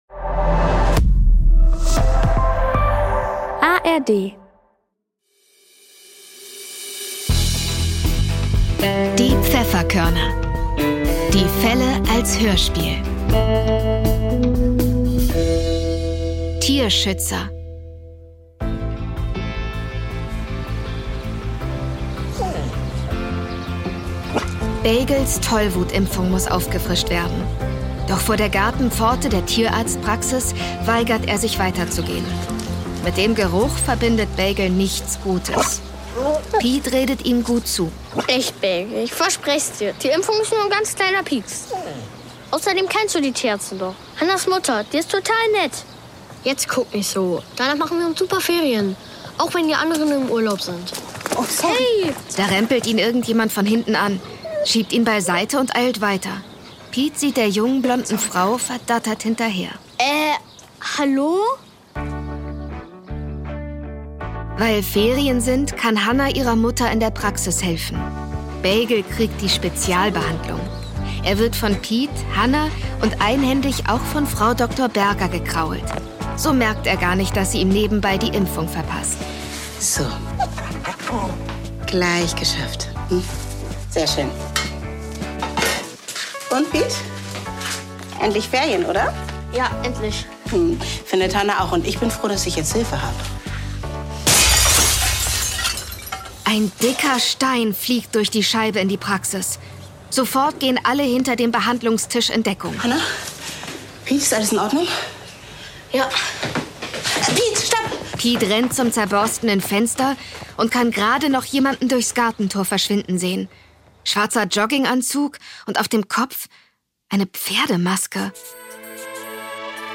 Tierschützer (8/21) ~ Die Pfefferkörner - Die Fälle als Hörspiel Podcast